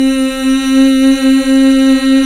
Index of /90_sSampleCDs/Club-50 - Foundations Roland/VOX_xFemale Ooz/VOX_xFm Ooz 1 M